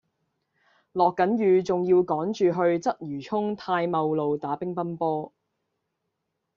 Голоса - Гонконгский 282